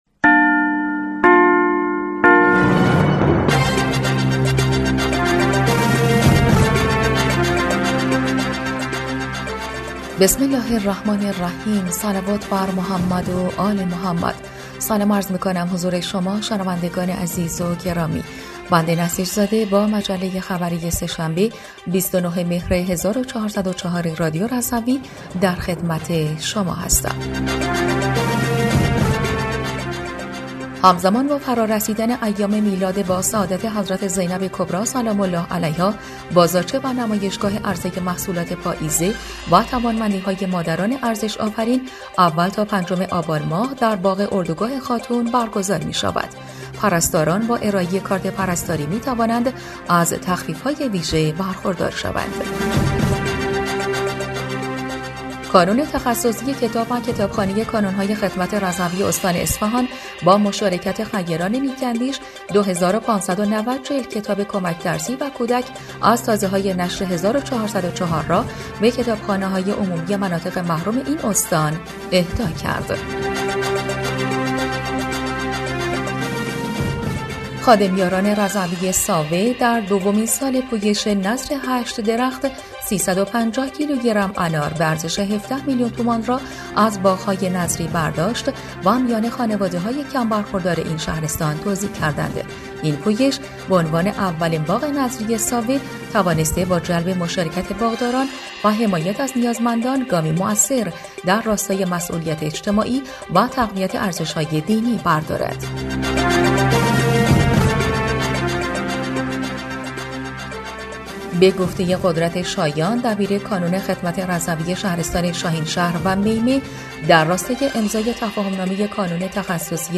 بسته خبری ۲۹ مهر ۱۴۰۴ رادیو رضوی؛